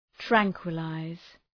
Προφορά
{‘træŋkwə,laız}
tranquilize.mp3